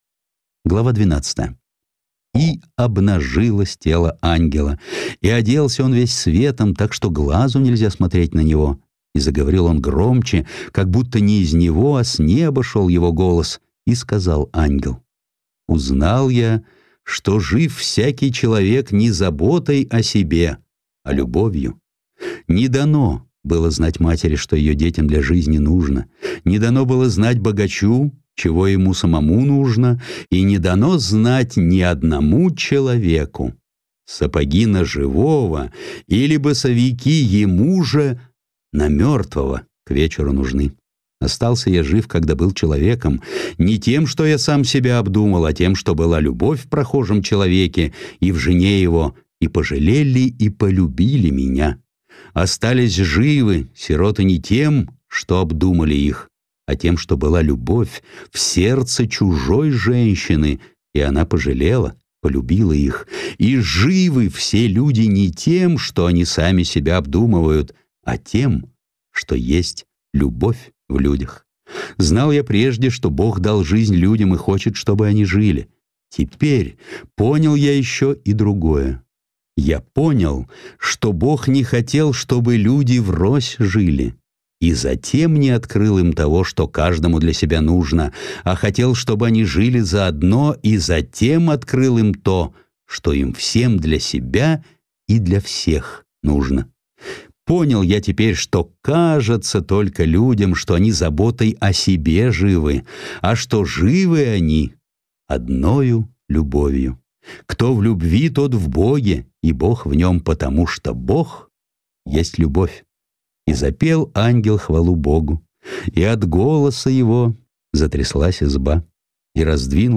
Жанр: Притча